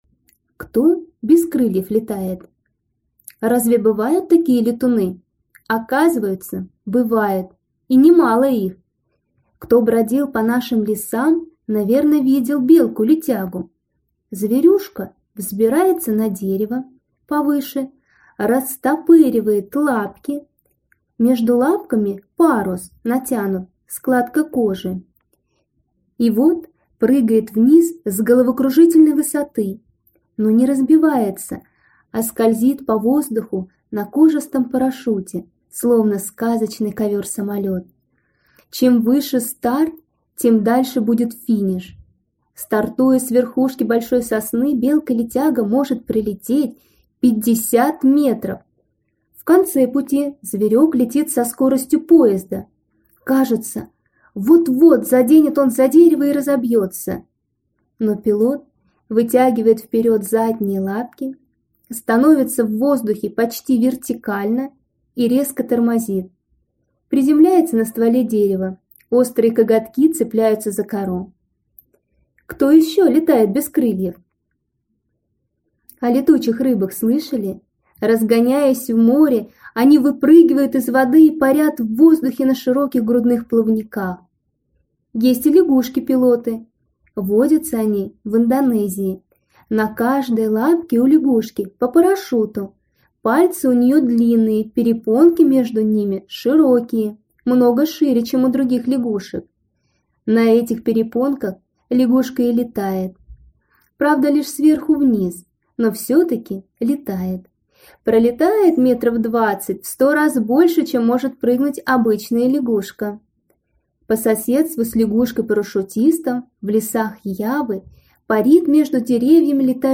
На данной странице вы можете слушать онлайн бесплатно и скачать аудиокнигу "Кто без крыльев летает" писателя Игорь Акимушкин.